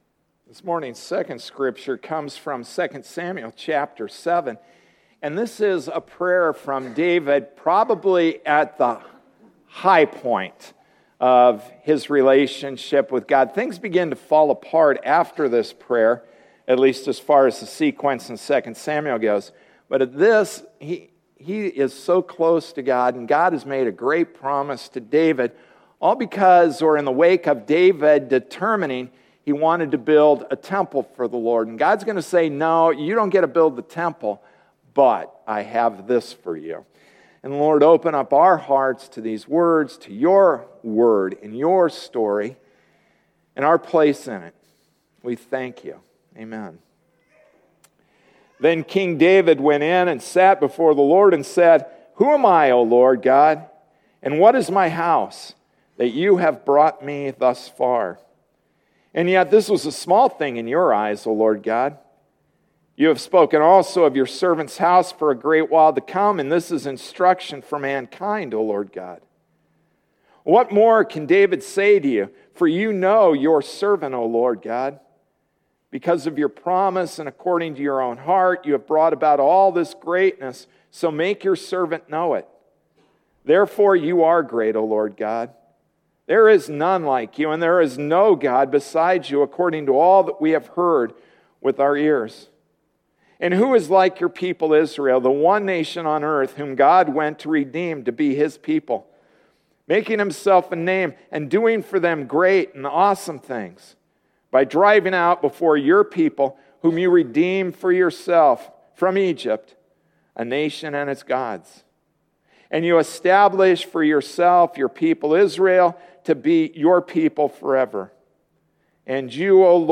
Passage: 2 Samuel 7:18-29 Service Type: Sunday Morning Service